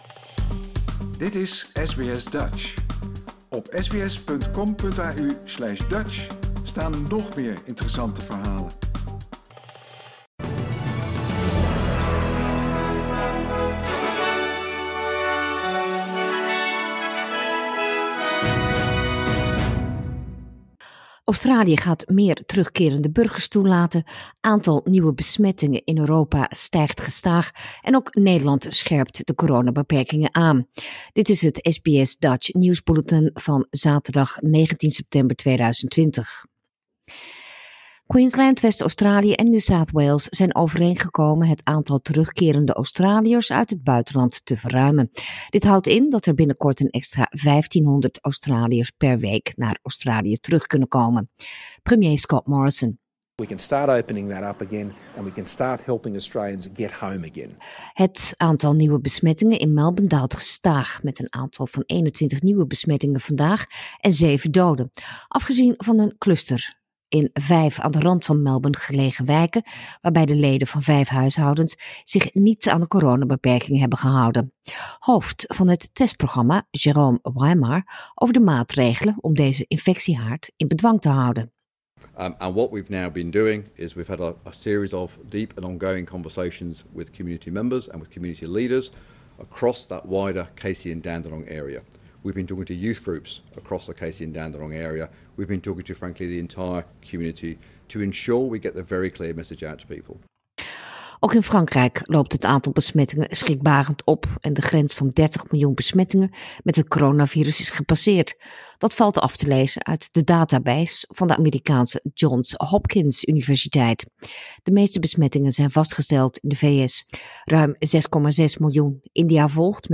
Nederlands/Australisch SBS Dutch nieuwsbulletin zaterdag 19 september 2020
dutch_1909_news.mp3